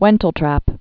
(wĕntl-trăp)